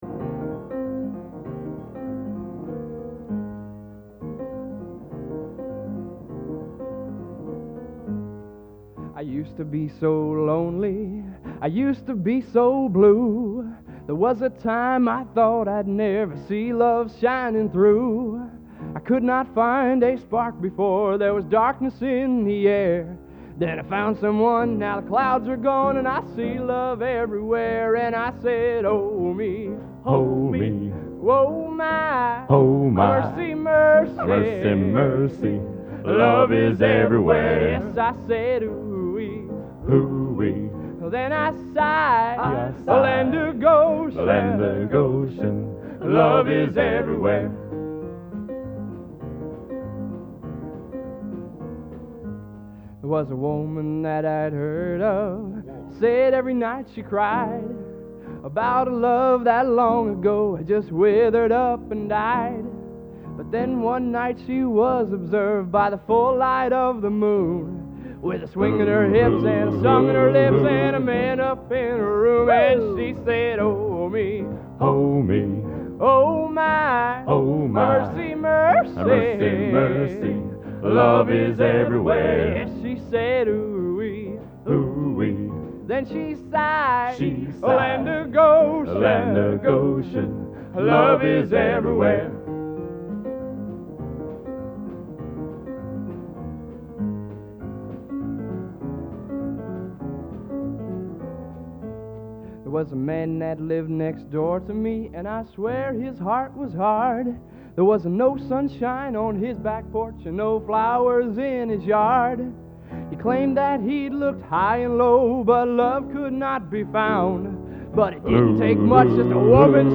Location: West Lafayette, Indiana
Genre: | Type: End of Season |Specialty